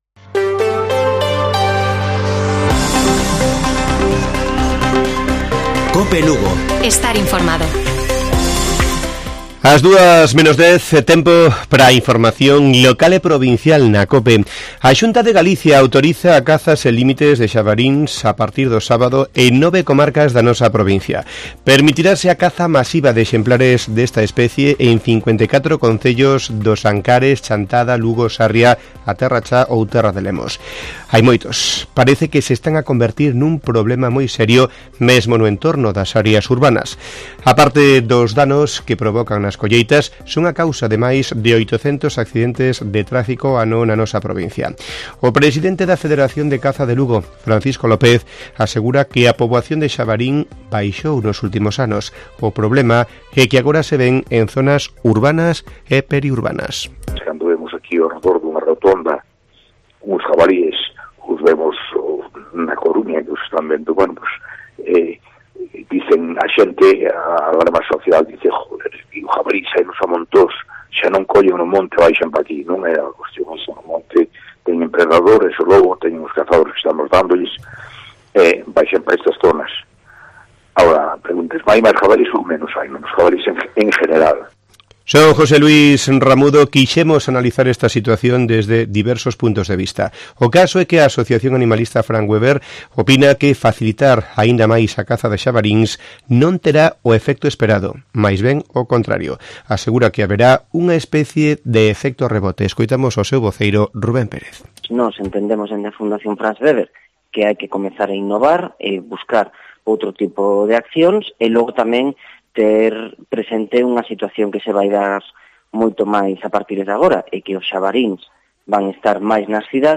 Informativo Mediodía de Cope Lugo. 14 de septiembre. 13:50 horas